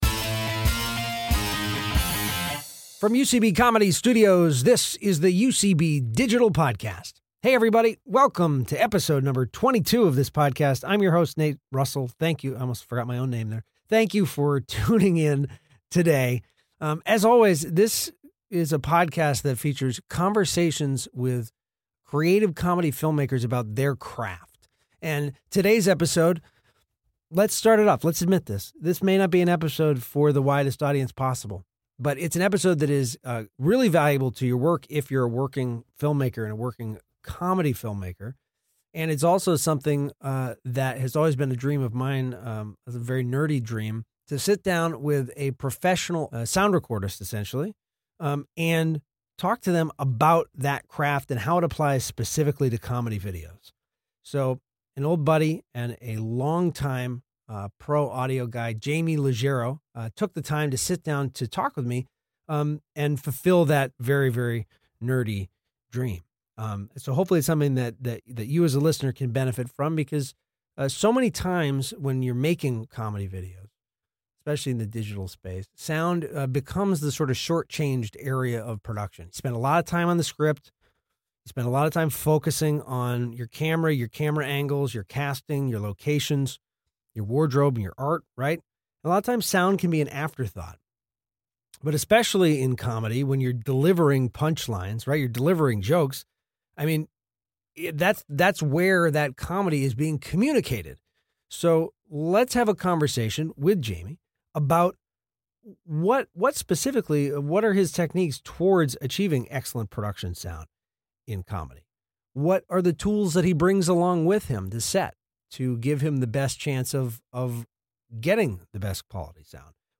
Recorded at UCB Comedy Studios East in New York City.